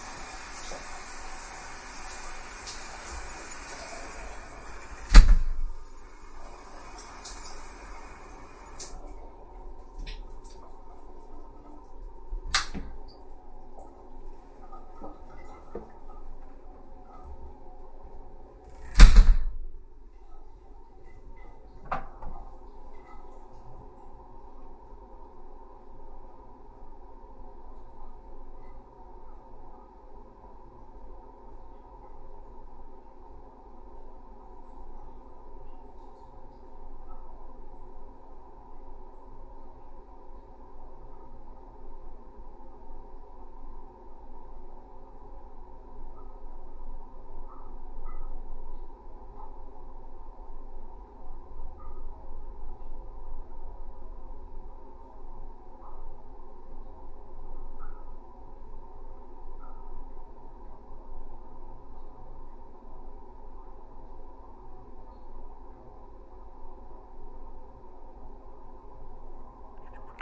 淋浴器打开运行，然后滴水
描述：淋浴开启然后滴水记录缩放H5
标签： 滴落 现场记录 漏极 淋浴器
声道立体声